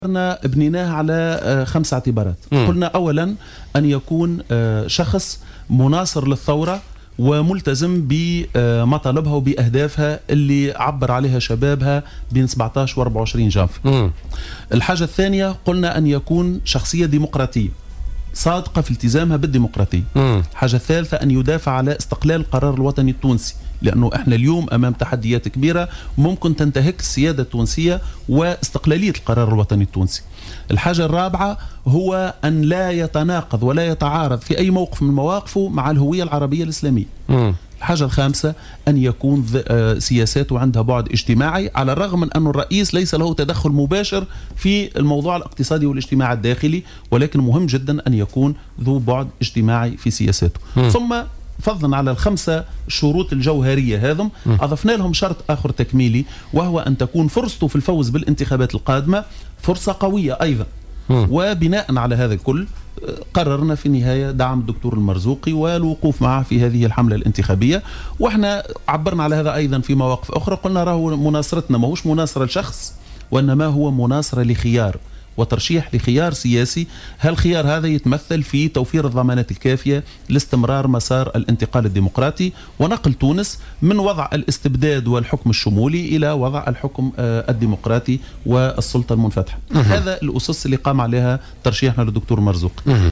أكد رياض الشعيبي أمين عام حزب البناء الوطني ضيف برنامج "بوليتيكا" اليوم الاربعاء أن حزبه سيواصل دعمه للمرشح للانتخابات الرئاسية المنصف المرزوقي في الدورة الثانية للانتخابات الرئاسية.